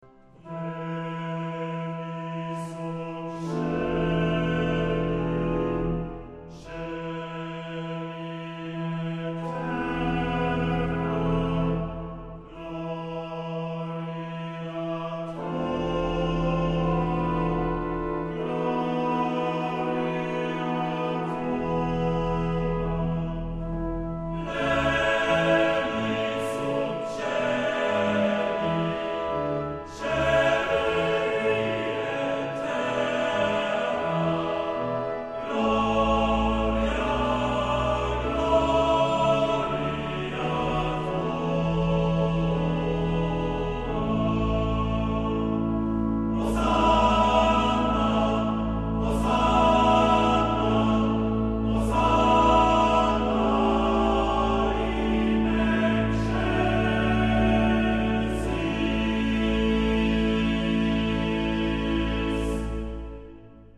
Genre-Stil-Form: geistlich ; romantisch ; Messe
Chorgattung: TBB  (3 Männerchor Stimmen )
Instrumente: Klavier (1)
Tonart(en): C-Dur